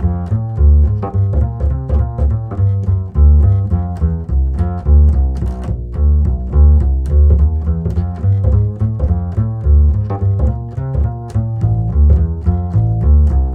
-JP WALK F.wav